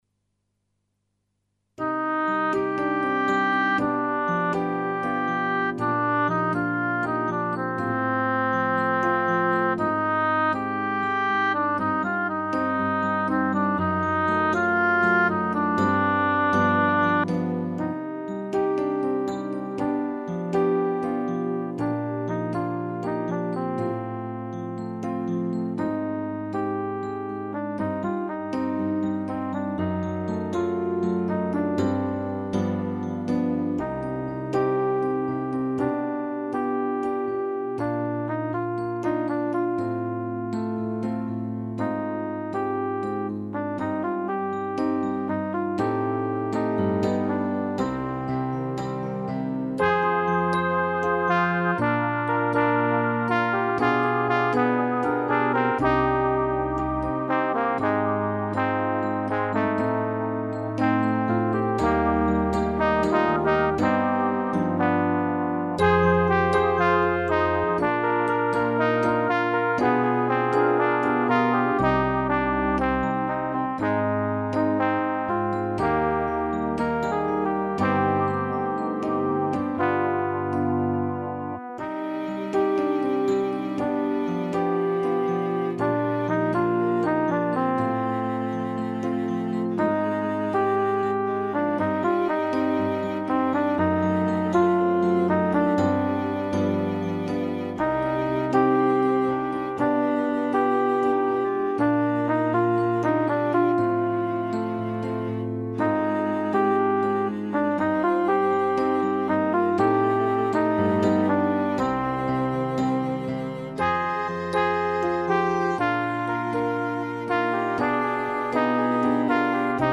fichier de travail pour la voix 2
( première voix d'accompagnement,